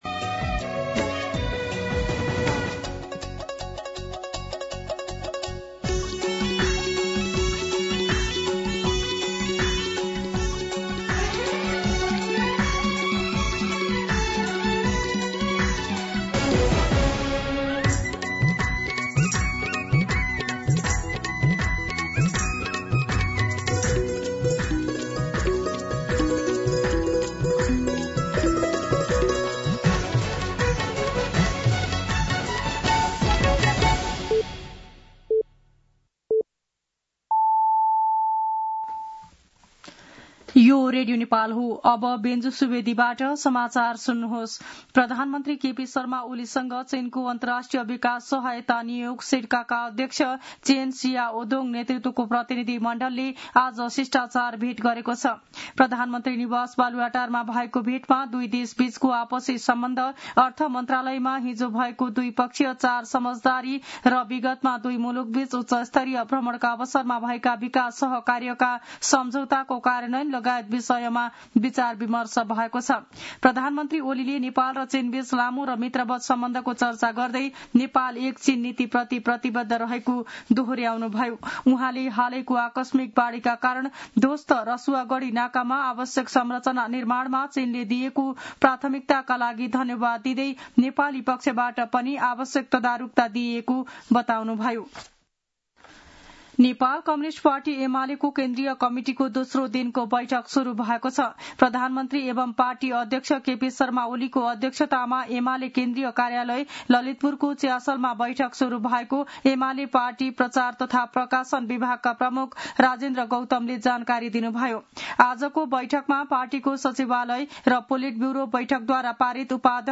दिउँसो १ बजेको नेपाली समाचार : ६ साउन , २०८२
1pm-News-06.mp3